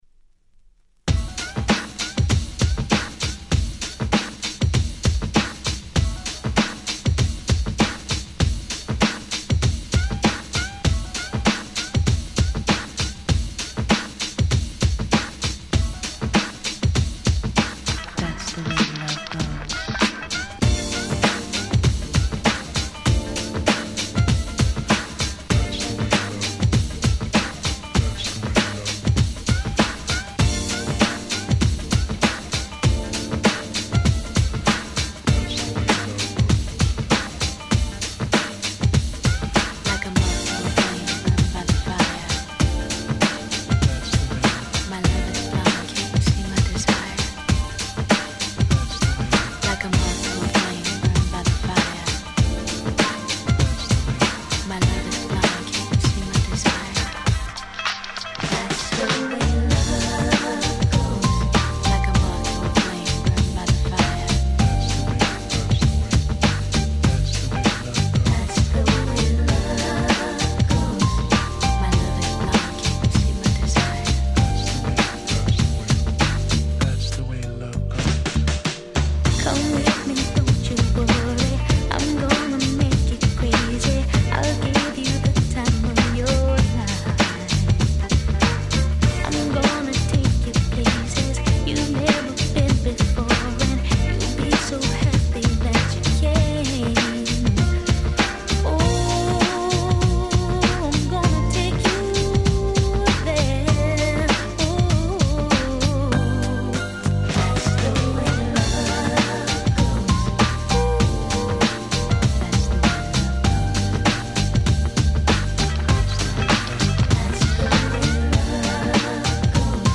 ウィッキド ウィッキード ミックス物 90's R&B Hip Hop 勝手にリミックス 勝手にRemix